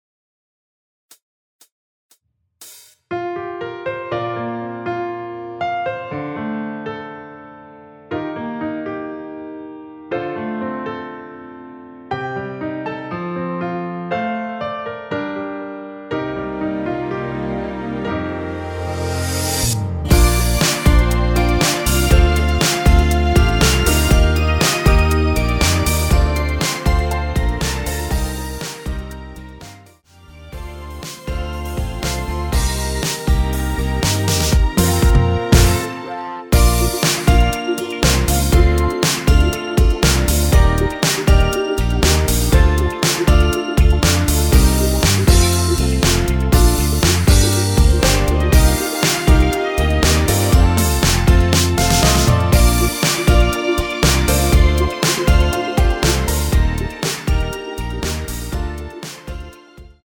전주 없이 시작 하는곡이라 카운트 4박 넣어 놓았습니다.(미리듣기 참조)
Bb
◈ 곡명 옆 (-1)은 반음 내림, (+1)은 반음 올림 입니다.
앞부분30초, 뒷부분30초씩 편집해서 올려 드리고 있습니다.
중간에 음이 끈어지고 다시 나오는 이유는